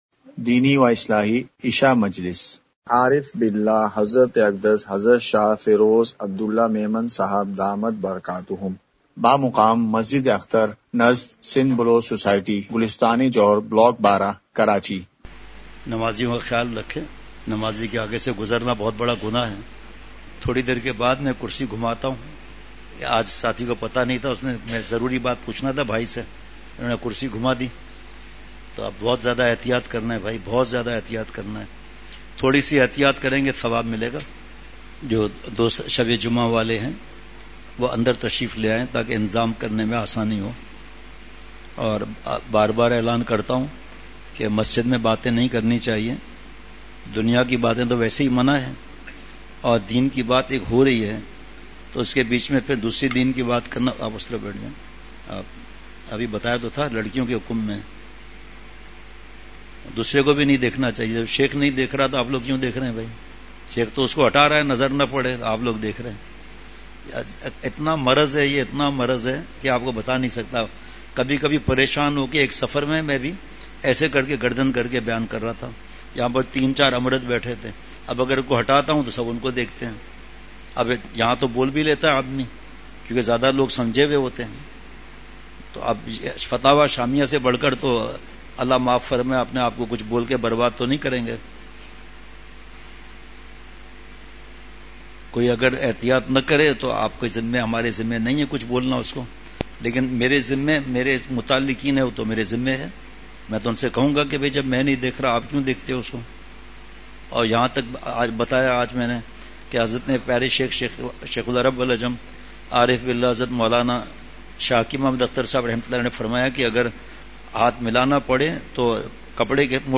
اصلاحی مجلس
*مقام:مسجد اختر نزد سندھ بلوچ سوسائٹی گلستانِ جوہر کراچی*